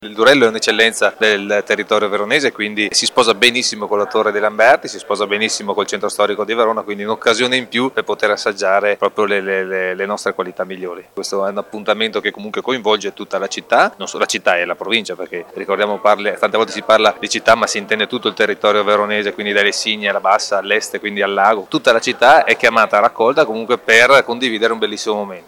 Sentiamo l’assessore al Tempo libero Filippo Rando: